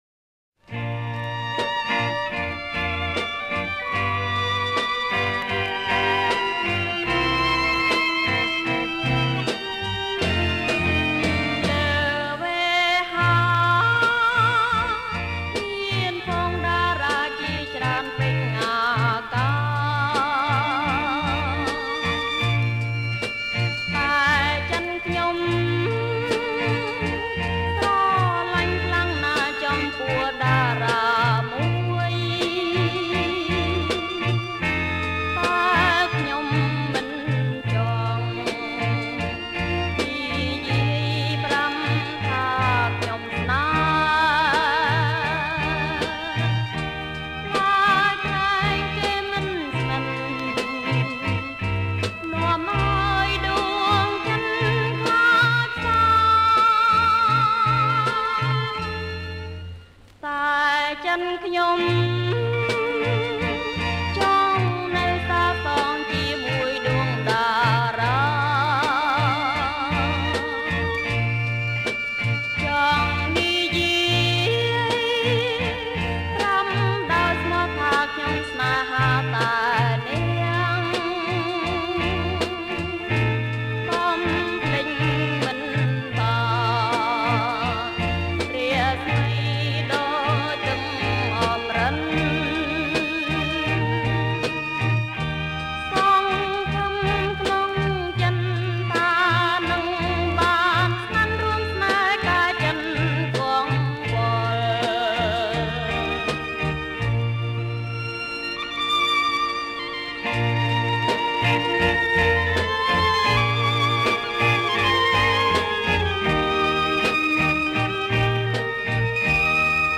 ប្រគំជាចង្វាក់ Rumba